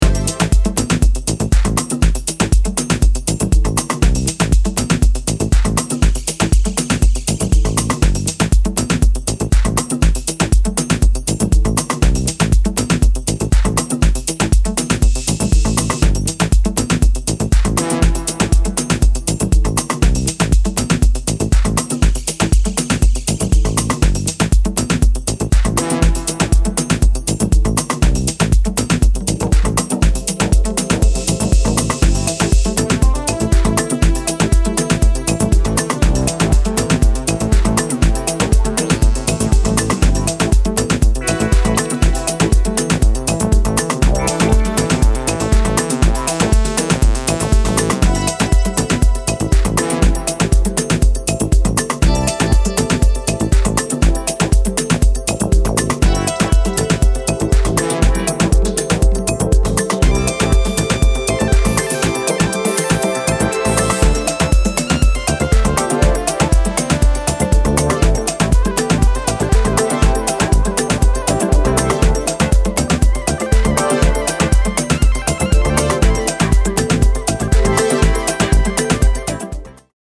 熱気を抑えながら、よりクールでディープなモダン・アフロ・ハウスへと仕立てたそちらも良し。